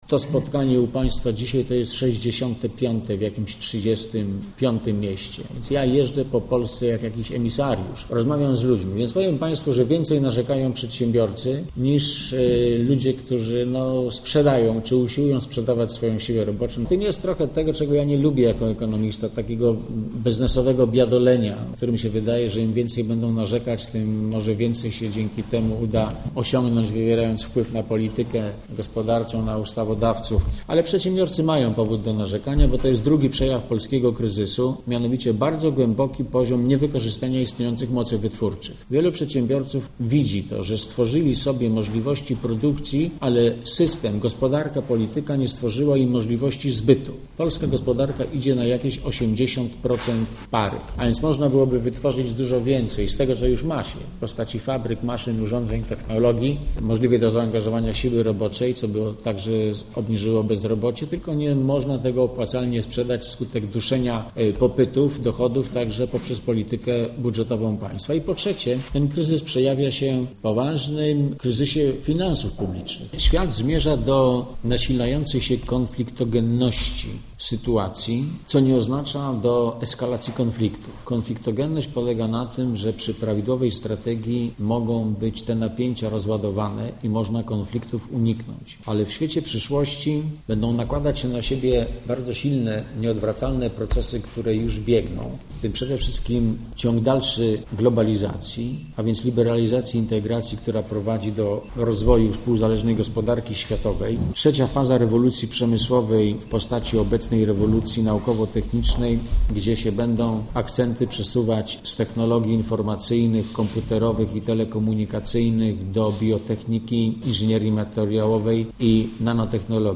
Byłego wicepremiera i ministra finansów zapytaliśmy także o podatek od kopalin, który w Zagłębiu Miedziowym wywołuje tak wiele emocji.